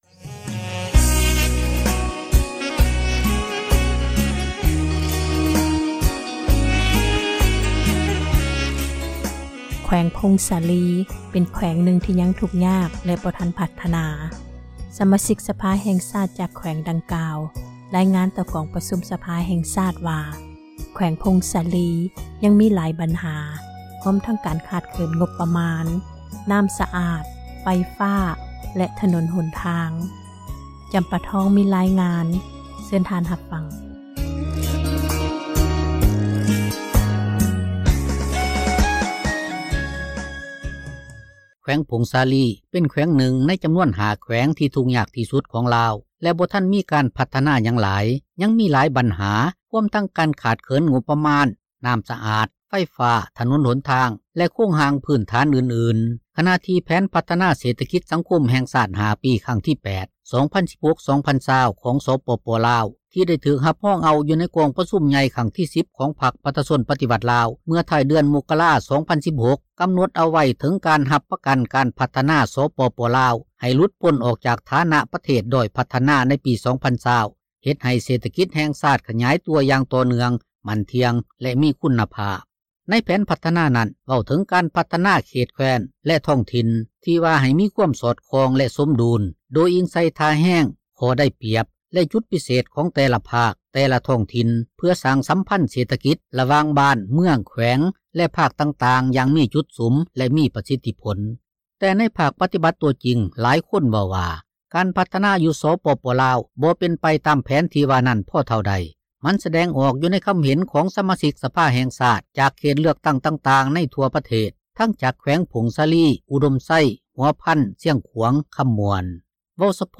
ເວົ້າສະເພາະ ແຂວງຜົ້ງສາລີ ຊຶ່ງເປັນແຂວງນຶ່ງ ທີ່ທຸກຍາກ ທີ່ສຸດ ແລະໄດ້ຮັບການ ພັທນາ ທັງບໍ່ຫລາຍ ແລະບໍ່ໄປບໍ່ມາ ພໍເທົ່າໃດ ດັ່ງທີ່ ດຣ.ຈັນສີ ແສງສົມພູ ກັມການພັກແຂວງ, ສະມາຊິກ ສະພາແຫ່ງຊາດ ກ່າວຕໍ່ ກອງປະຊຸມ ສະພາ ໃນນາມ ຕາງໜ້າ ສະມາຊິກ ສະພາ ແຫ່ງຊາດ ເຂດເລືອກຕັ້ງທີ 2 ແຂວງຜົ້ງສາລີ ກ່ຽວກັບ ໂຄງການພັທນາ ແລະ ງົບປະມານ ທີ່ທາງ ຣັຖບາລ ຈັດສັນໃຫ້ ຕໍ່າທີ່ສຸດ ນັ້ນວ່າ: ສວຽງ...